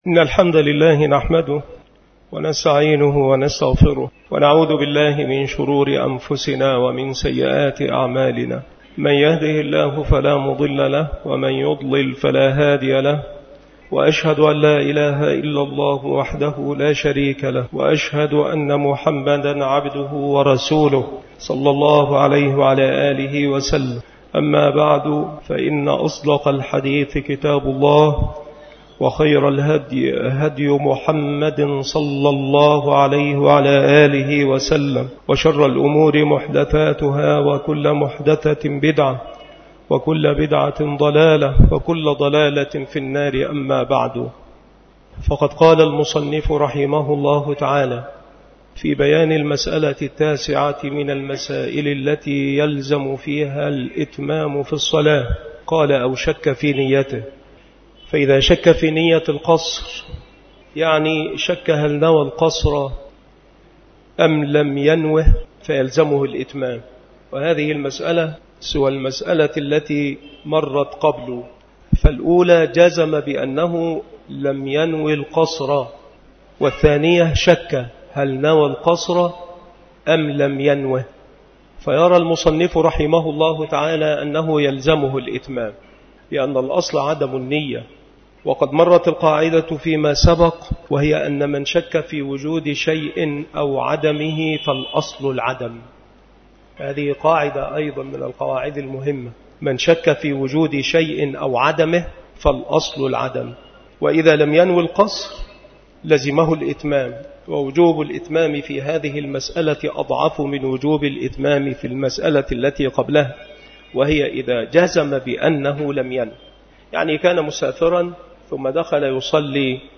مكان إلقاء هذه المحاضرة بمسجد صلاح الدين بمدينة أشمون - محافظة المنوفية - مصر